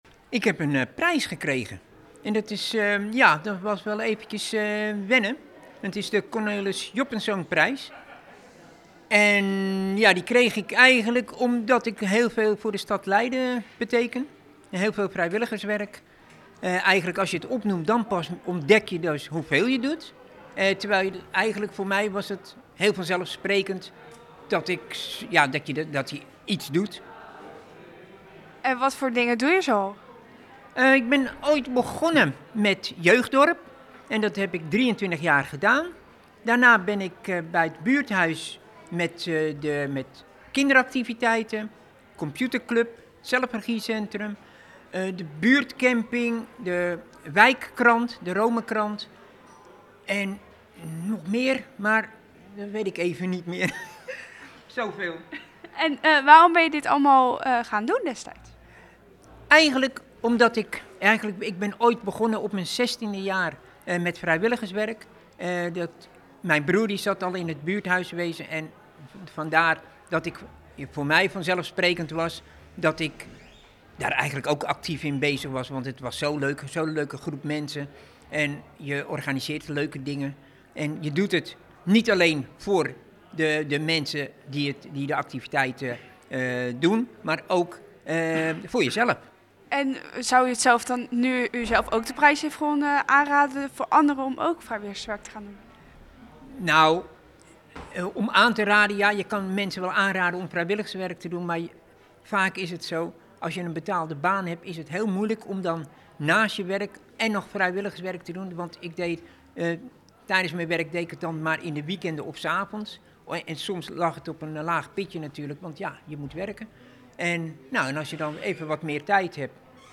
Verslaggever